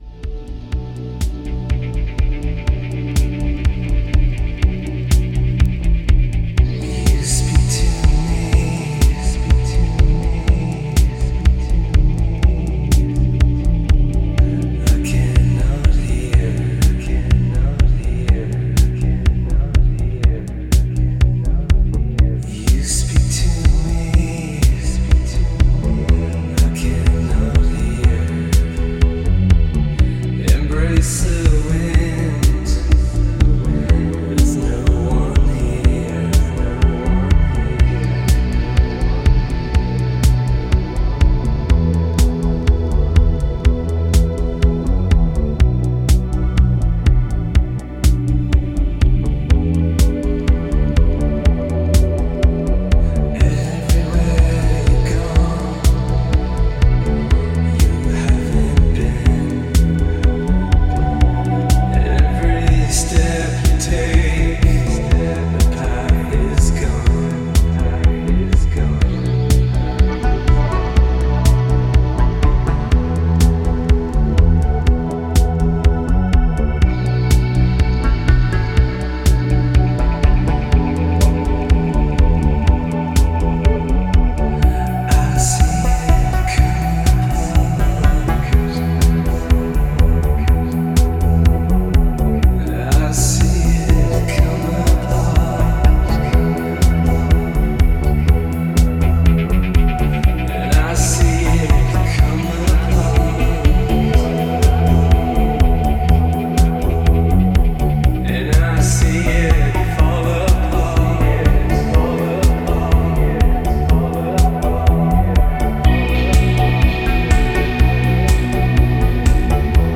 Sweet & deep vocal minimal house tracks
House